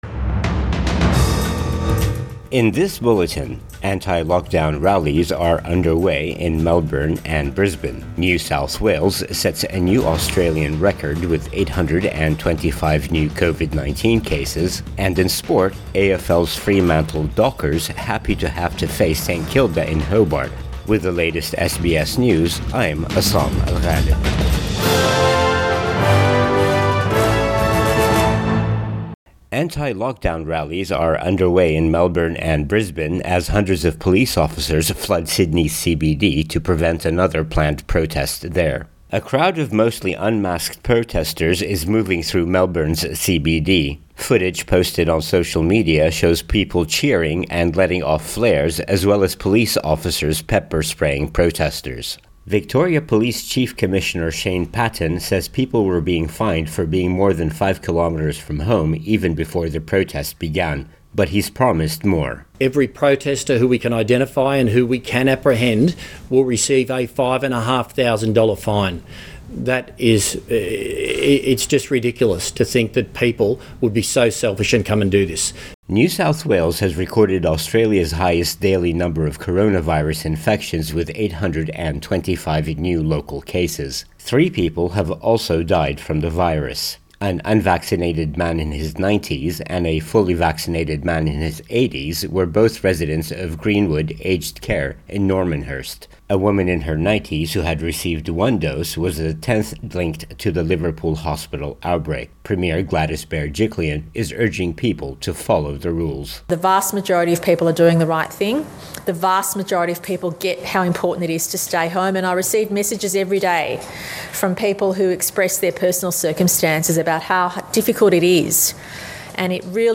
PM bulletin 21 August 2021